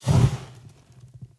Light Torch 1.ogg